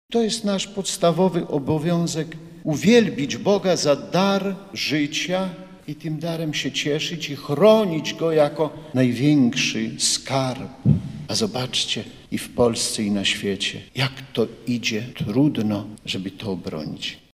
W homilii bp Kamiński podkreślił, że Królestwo Boże ma przede wszystkim charakter duchowy.